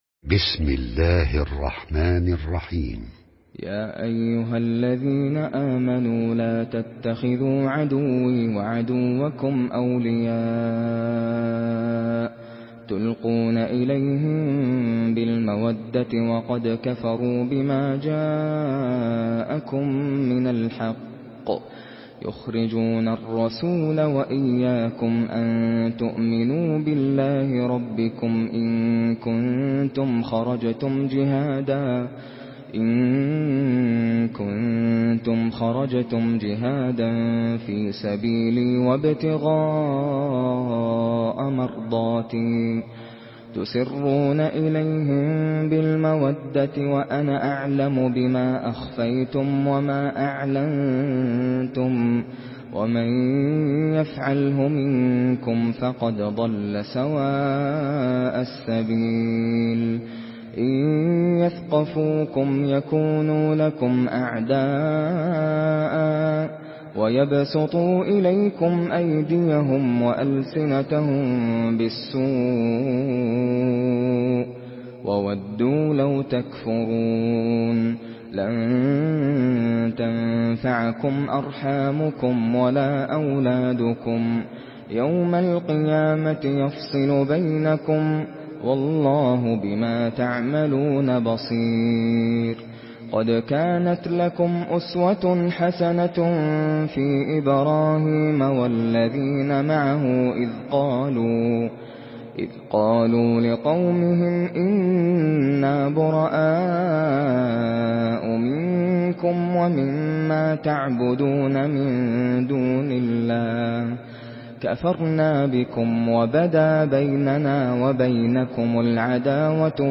Surah Al-Mumtahinah MP3 by Nasser Al Qatami in Hafs An Asim narration.
Murattal